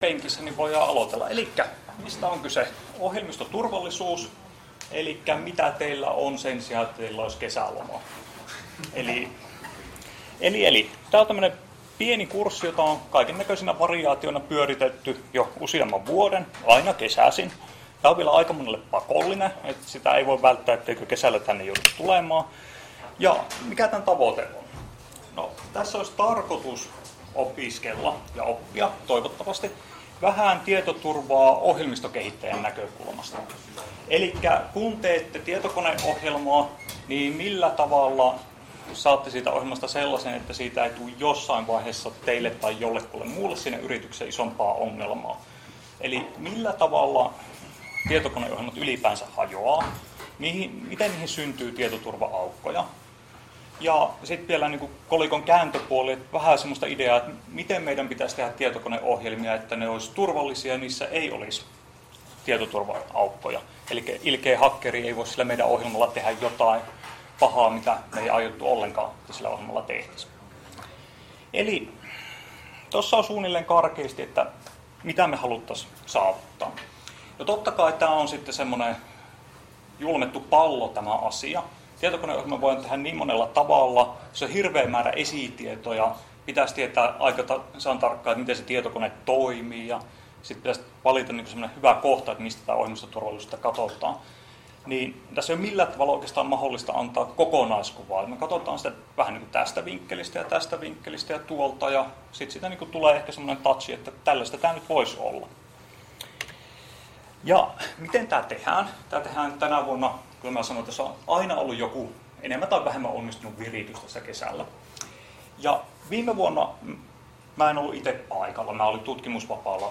Luento 22.5. - Osa 1 — Moniviestin